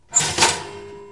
家用 " 烤面包机 03
描述：烤面包机